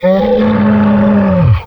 MONSTER_Groan_Long_mono.wav